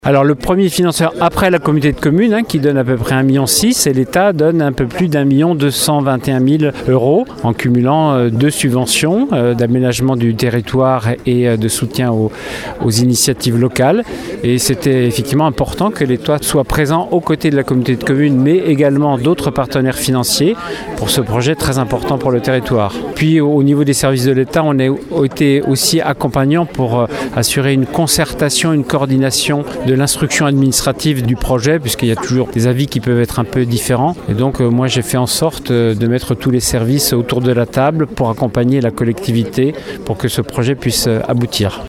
L’État qui s’est révélé être un allié de poids dans ce projet, comme le rappelle le sous-préfet de Rochefort Stéphane Donnot :